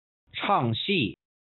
唱戏\chàngxì\Cantando